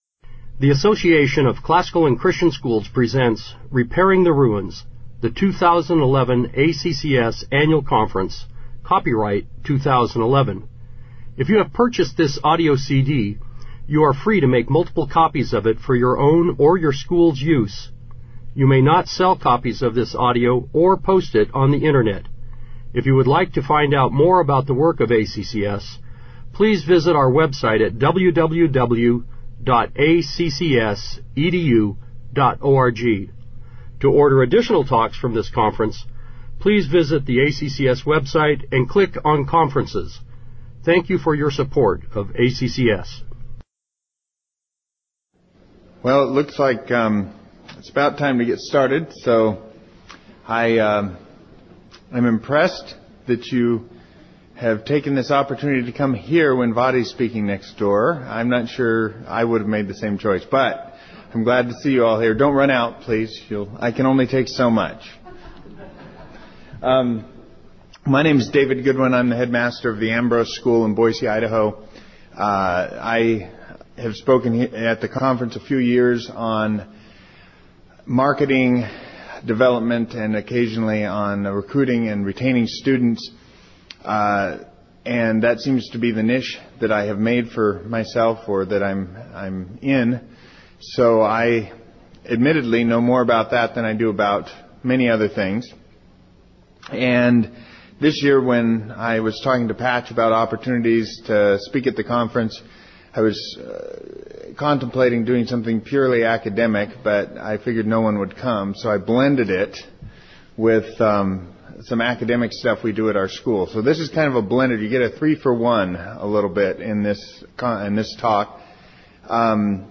2011 Workshop Talk | 1:05:32 | All Grade Levels, Leadership & Strategic